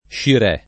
vai all'elenco alfabetico delle voci ingrandisci il carattere 100% rimpicciolisci il carattere stampa invia tramite posta elettronica codividi su Facebook Scirè [ + šir $+ ] top. m. (Et.) — regione dell’Etiopia settentrionale